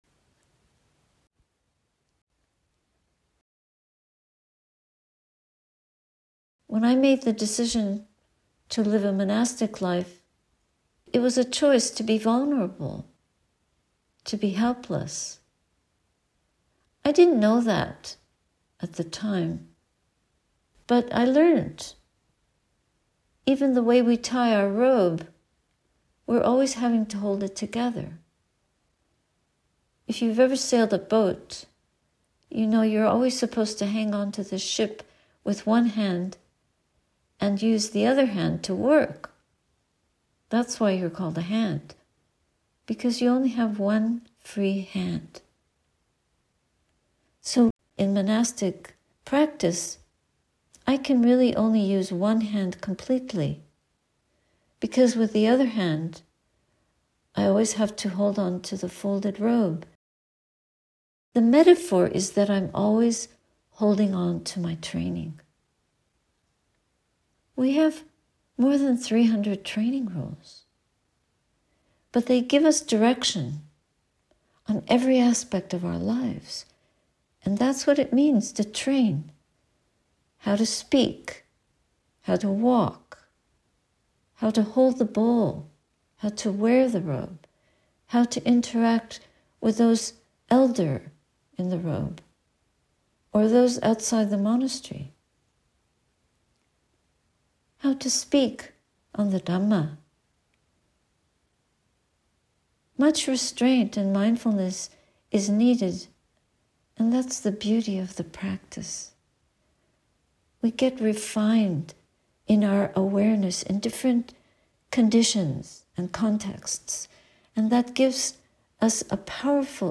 OBS retreat, Arnprior, Ontario, 2007.